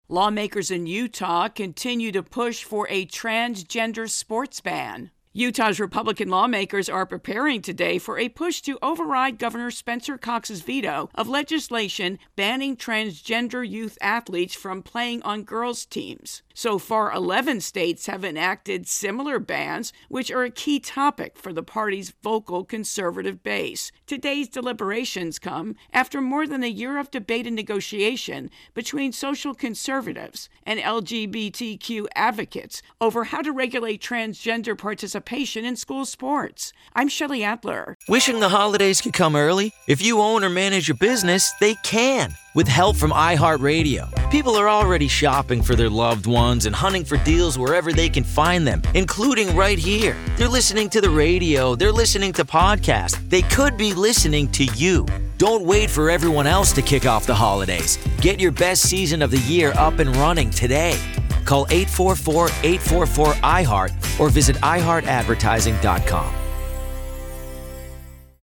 Ban intro and voicer